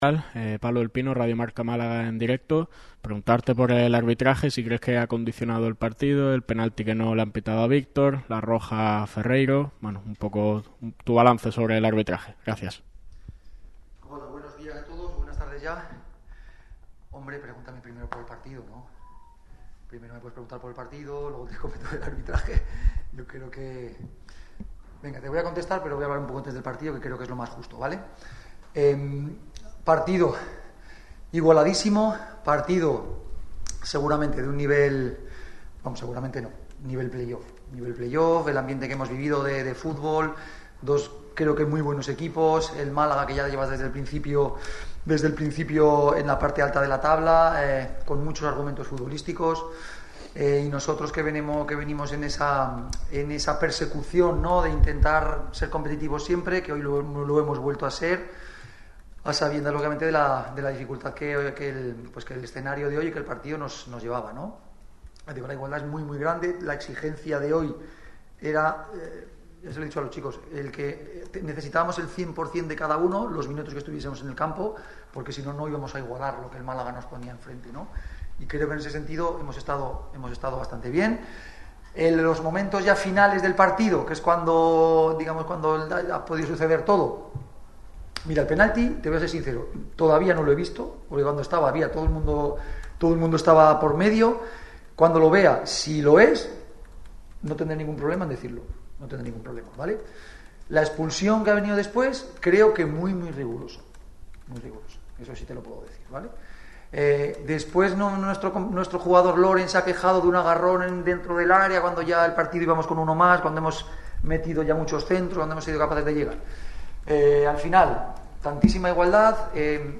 De esta forma, el técnico del Murcia no ha querido mojarse sobre la acción de la pena máxima porque no la ha visto antes de llegar a la rueda de prensa.